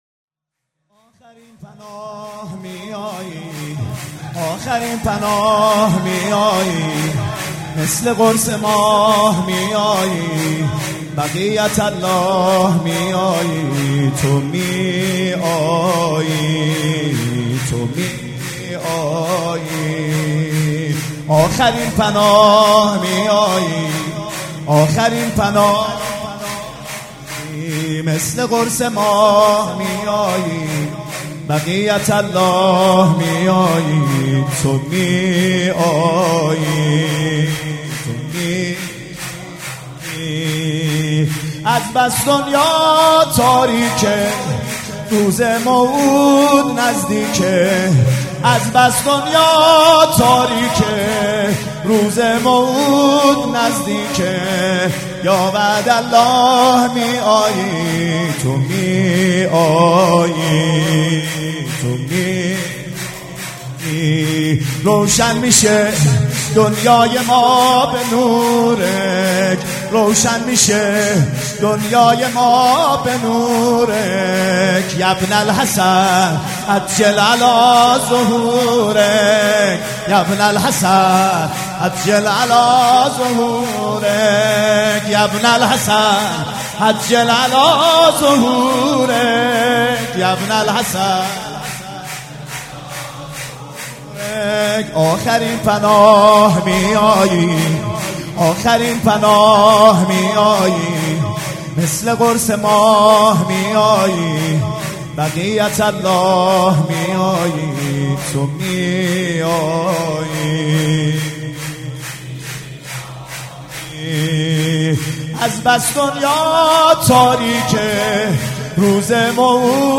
شور
مداح
جشن نیمه شعبان
شور3.mp3